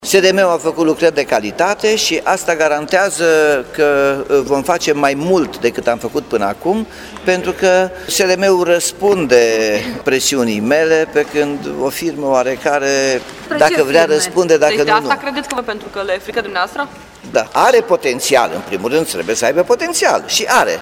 Primarul Nicolae Robu susține că decizia ar trebui să însemne străzi și trotuare mai bune: